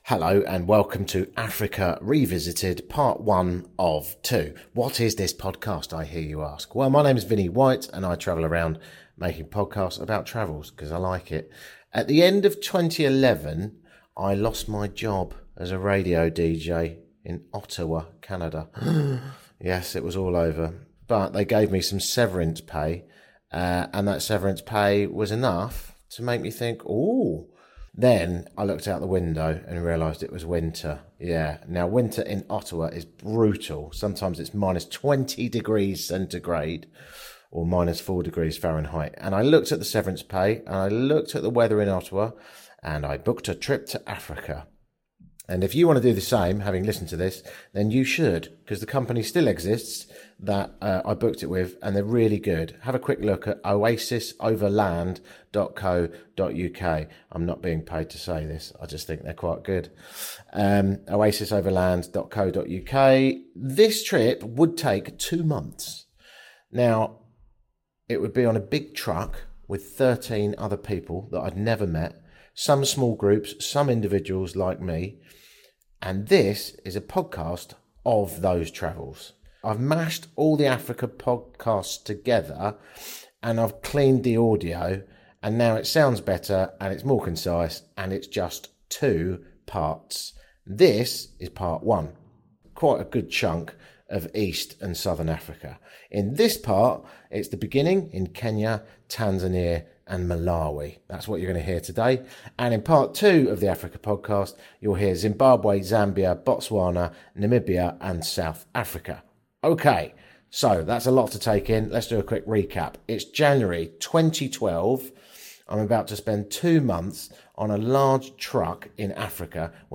Two months, 8 countries, lots of laughs. This is that adventure, from back in 2012. Two part podcast, edited and audio-improved for today.